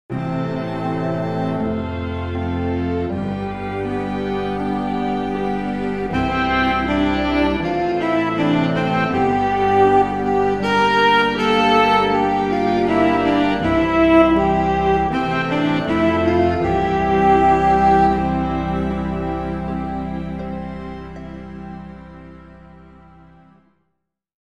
The “Save us” tune is probably the pick of them, or at least the most distinctive.